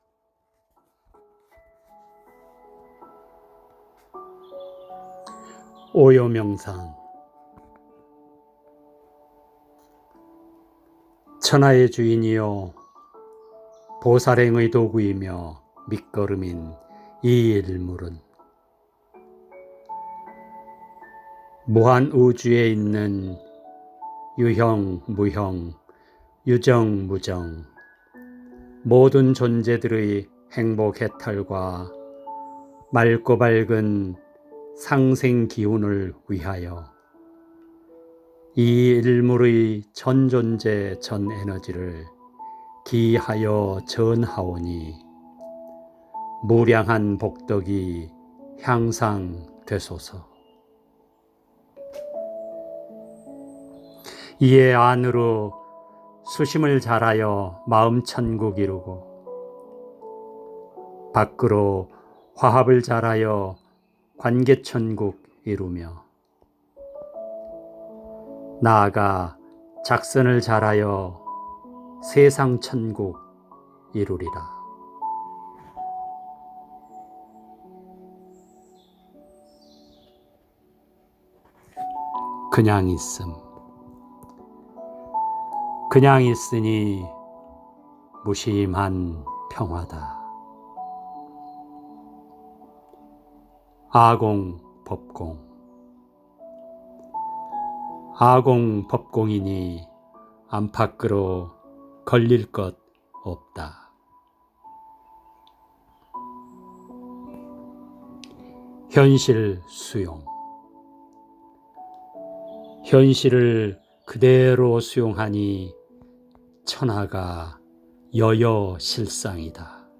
전문 스튜디오의 녹음은 아닐지라도 스마트 폰으로 할 수 있는 최상의 버전 으로 생각됩니다. 다운 받아 들으시고 오요 생각체계로 행복해탈 누리시길 기원드려봅니다.